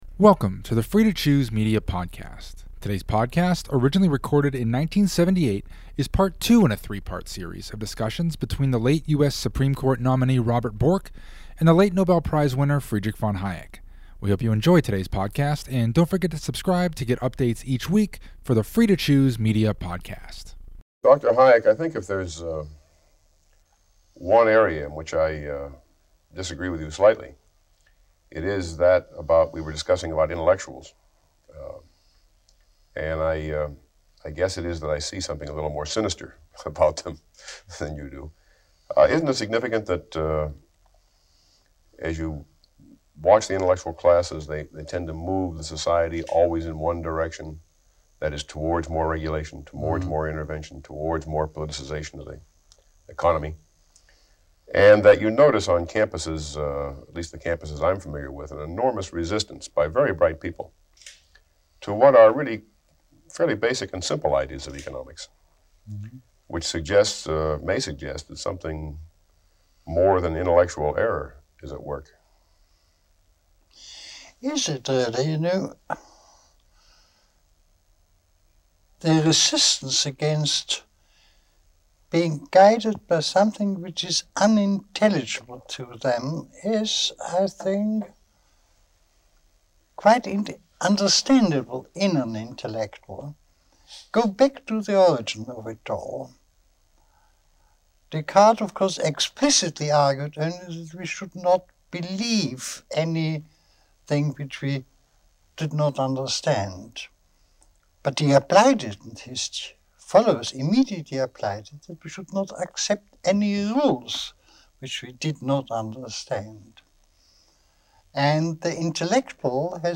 It was recorded in 1978.